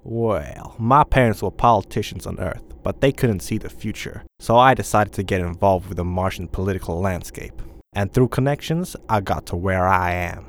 Voice Lines